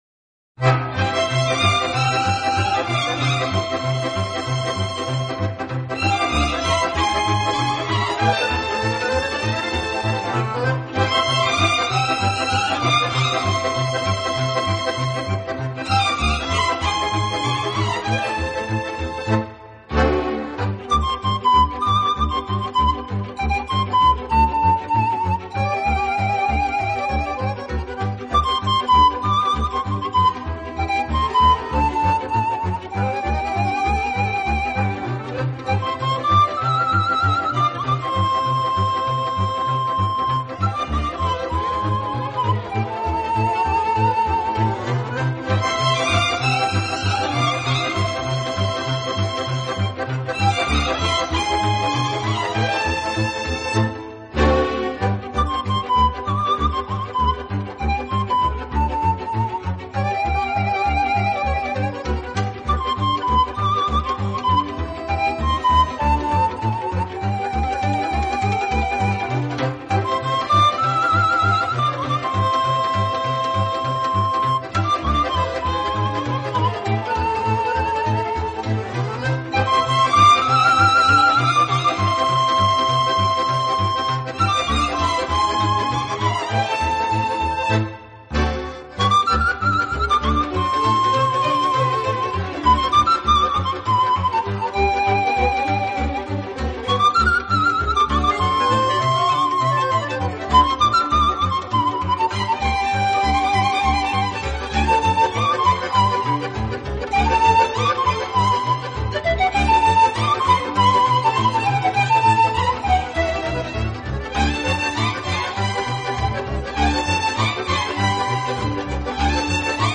超广角音场的空间感演绎，大自然一尘不染的精华，仿佛让你远离凡尘嚣暄，